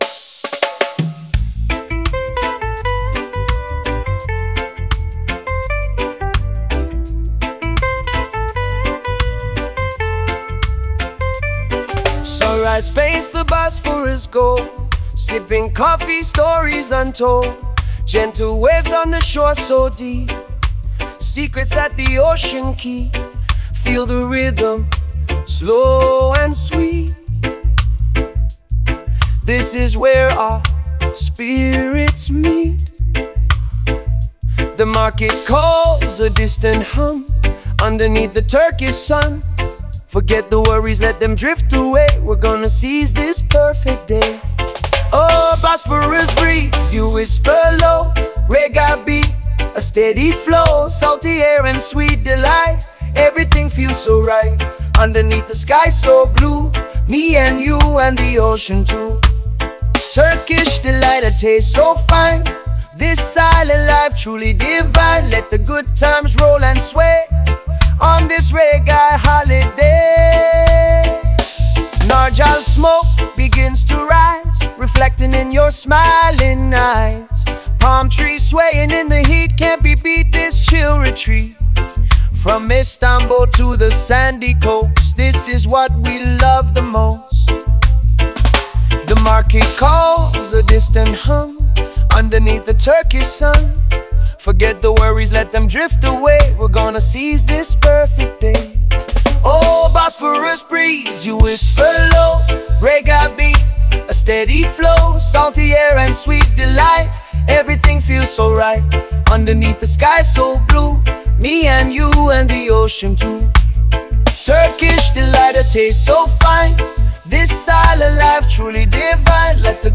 A chill raggea style song with a beach vibe and Turkish ambiance